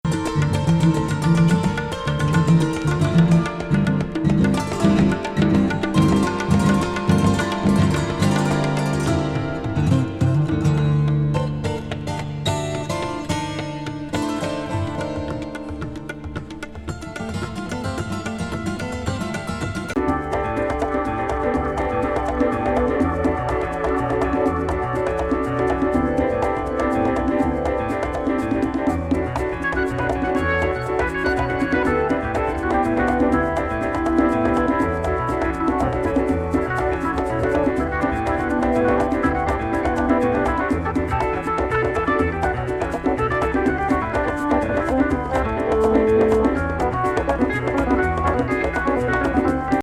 ハモンド・ナンバー